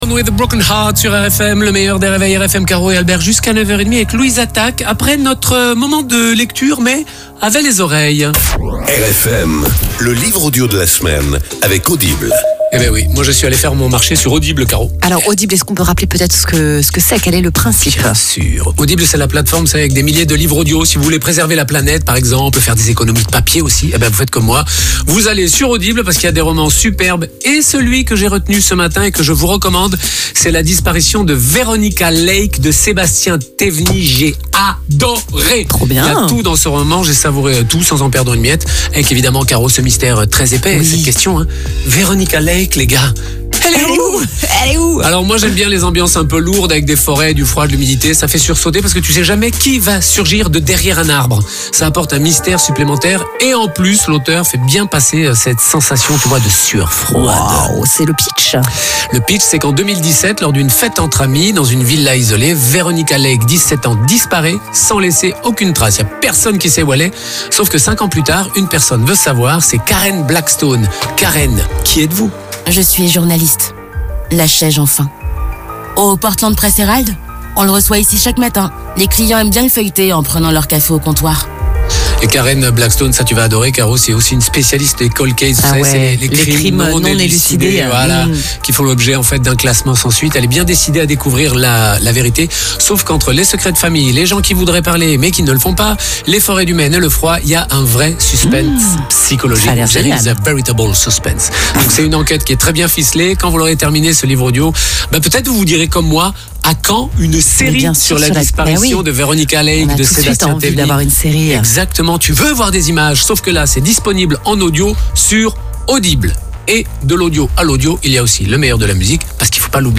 Chaque semaine, une chronique « Le livre audio de la semaine » est intégrée en direct dans les matinales et créneaux clés d’Europe 1, RFM et Europe 2. Animées par les voix emblématiques des stations, ces prises de parole courtes (30″) mêlent extrait sonore du livre et pitch de recommandation.